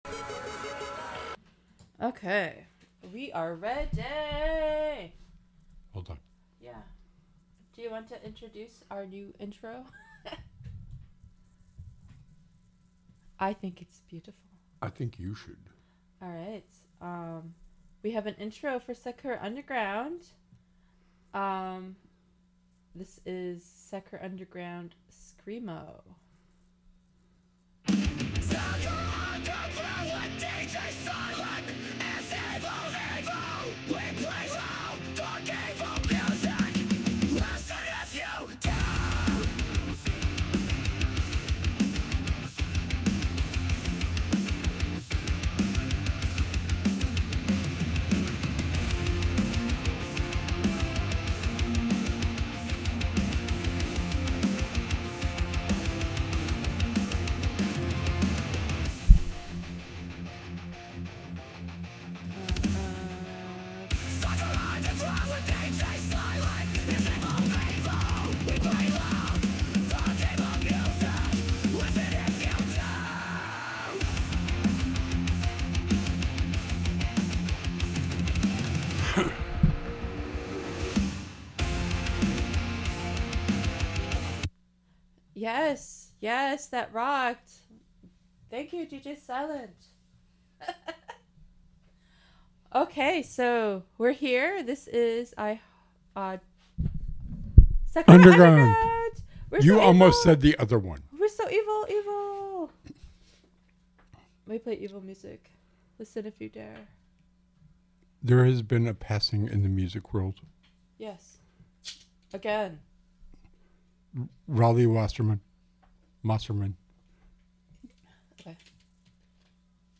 This was recorded at the Valley Free Radio studios in Northampton MA USA on Sept 21st 2024.
SakuraUnderground is a podcast that features a mix of music, witty commentary, and sing-alongs.